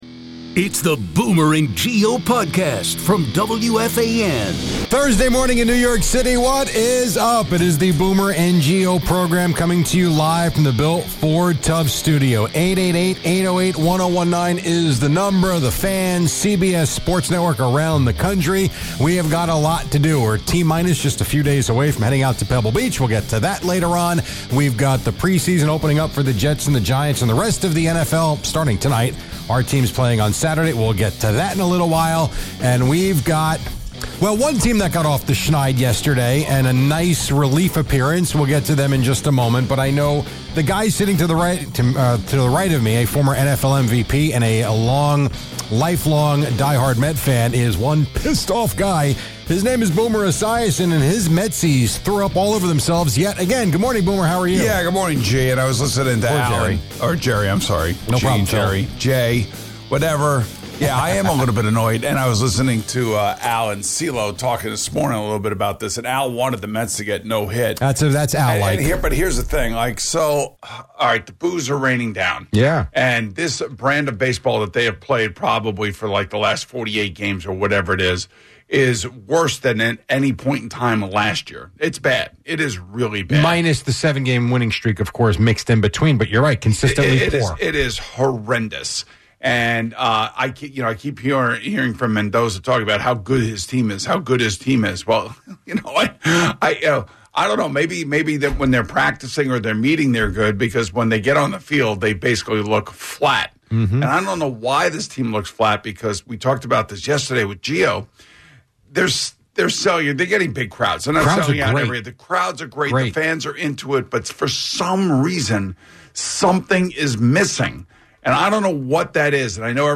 It is the Boomer NGO program coming to you live from the built Ford Tubb Studio.